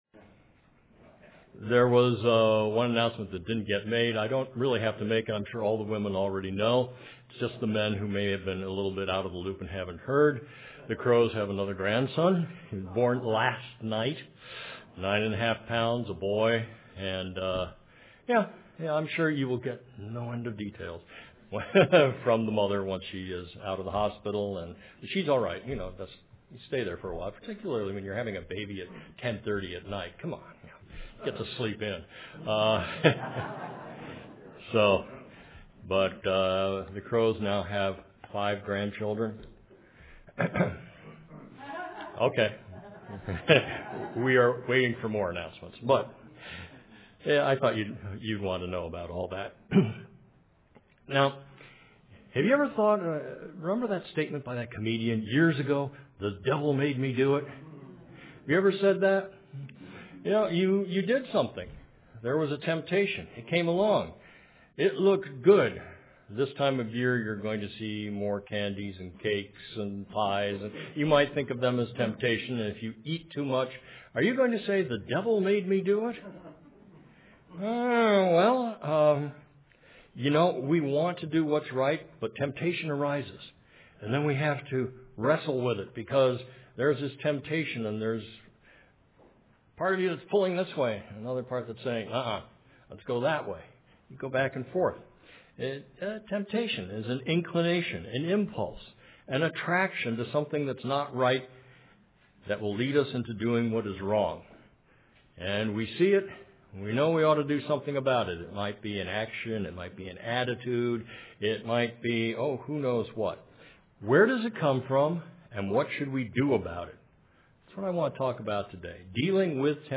A partial list of the Scriptures used in this sermon: